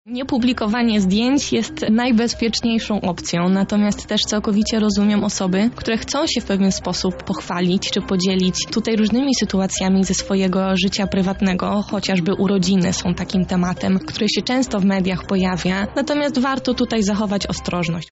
Odpowiedź na to i inne pytania poznaliśmy podczas dzisiejszej Porannej Rozmowy Radia Centrum.